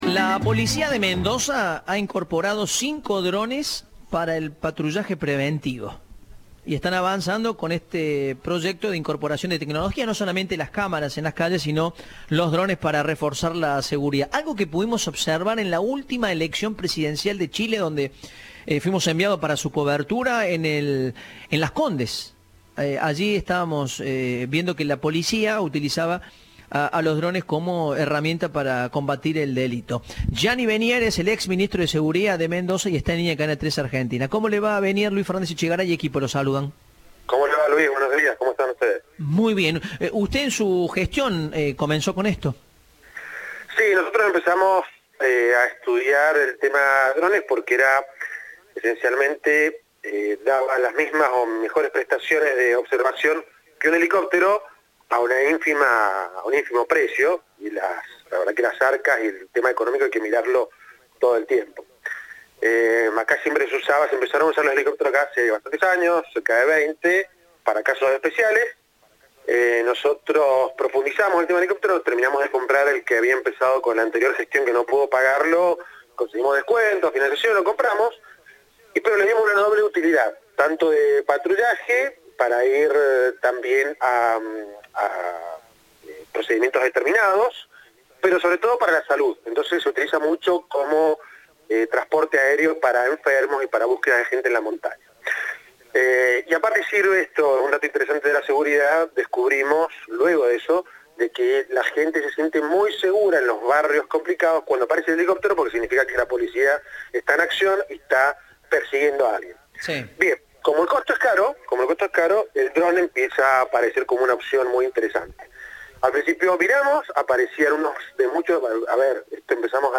Gianni Venier, ex ministro de Seguridad de Mendoza, dijo a Cadena 3 que esta propuesta se empezó a estudiar como una alternativa a los costos que implica el patrullaje en helicópteros.